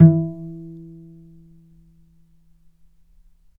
healing-soundscapes/Sound Banks/HSS_OP_Pack/Strings/cello/pizz/vc_pz-E3-mf.AIF at ae2f2fe41e2fc4dd57af0702df0fa403f34382e7
vc_pz-E3-mf.AIF